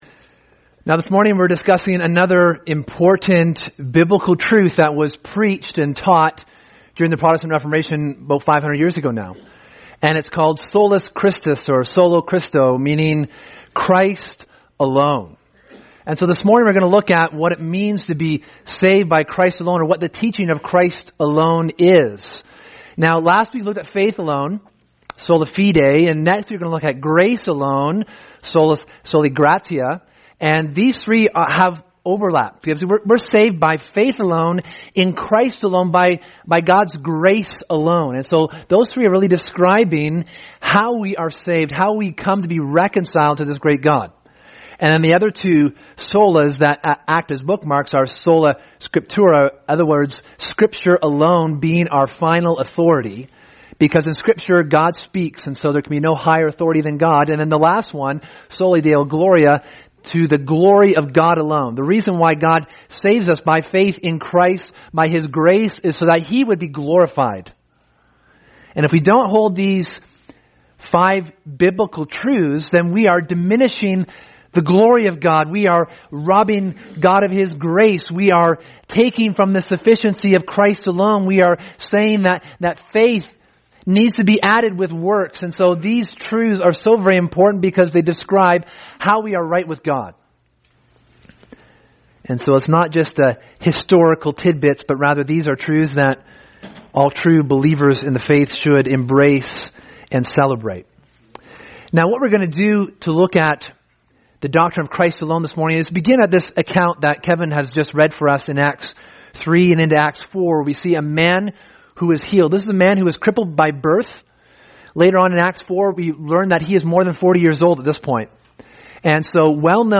Sermon: Christ Alone